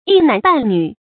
一男半女 注音： ㄧ ㄣㄢˊ ㄅㄢˋ ㄋㄩˇ 讀音讀法： 意思解釋： 指一個子女，一個兒子或女兒。